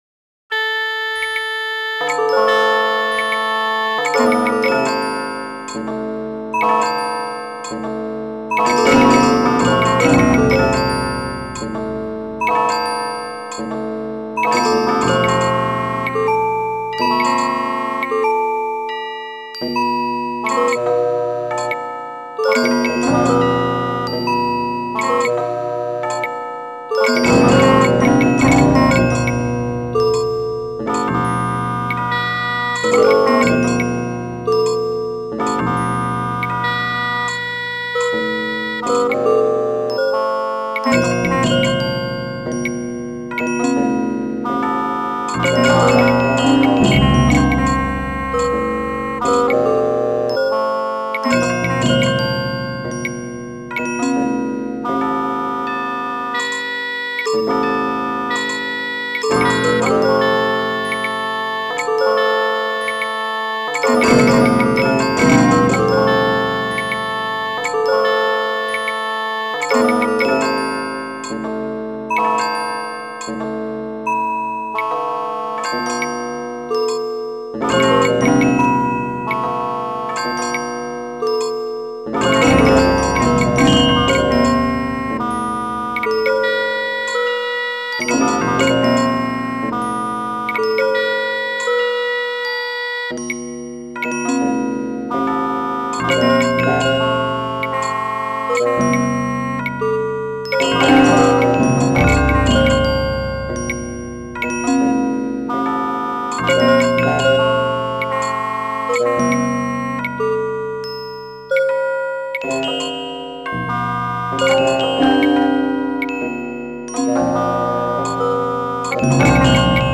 These mp3s were rendered to audio with the Roland Sound Canvas.
So, in these recordings, they fade out at the end of the clip.
heavenly_chimes_scale_with_shanai_etc_4_mins.mp3